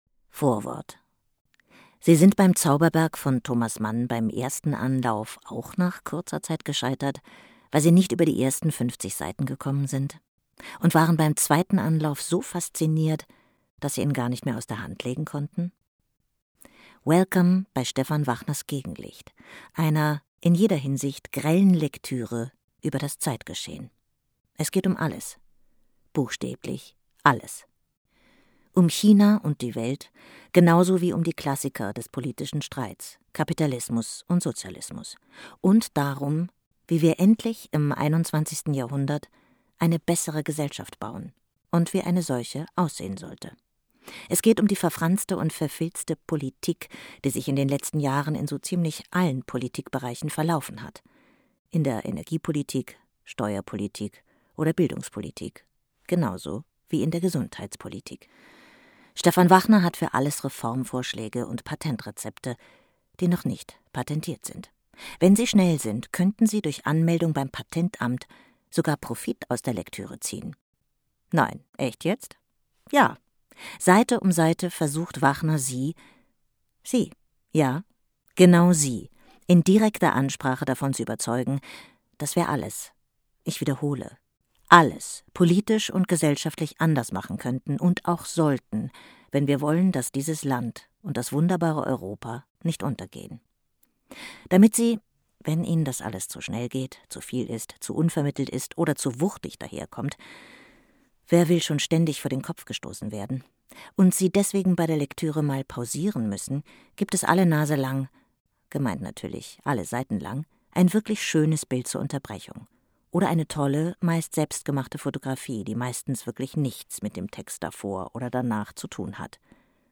Hörbücher vom Montalto Verità Verlag